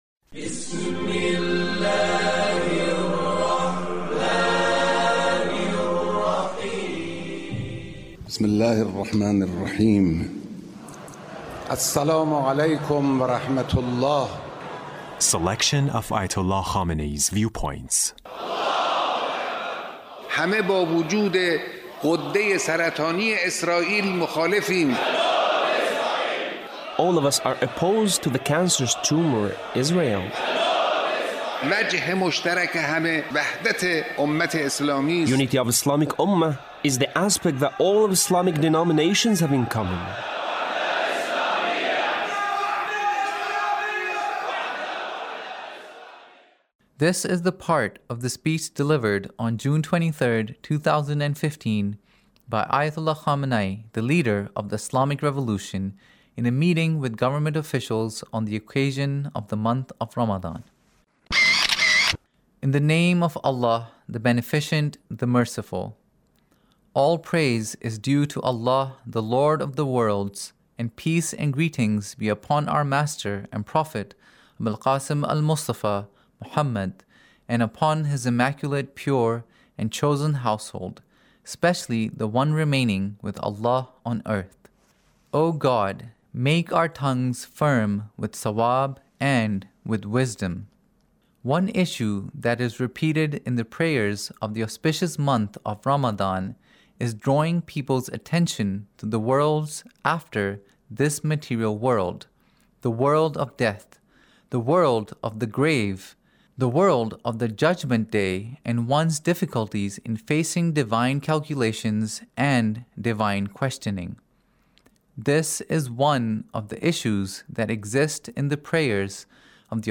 Leader's Speech On The Month of Ramadhan